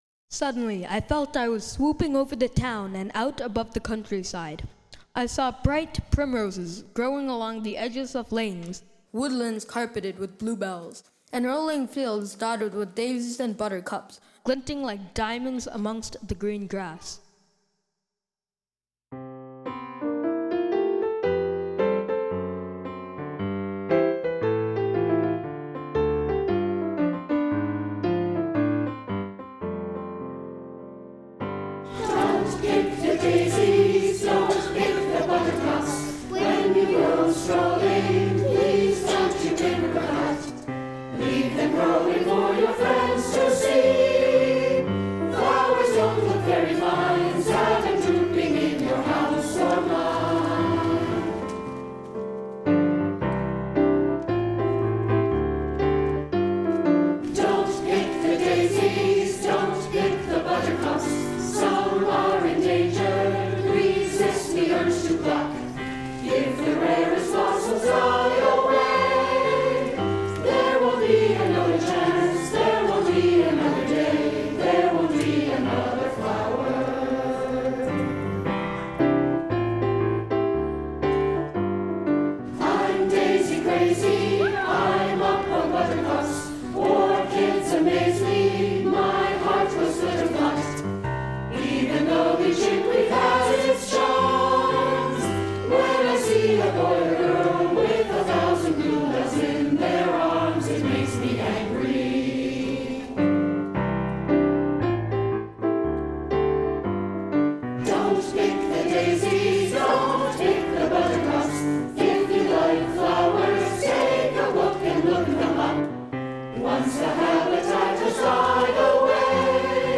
piano
Below are summaries and recordings of the songs of Powers of Ten as performed by the 2014 NCFO Festival Chorus.